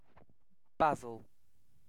Ääntäminen
UK RP : IPA : /ˈbæzˌəl/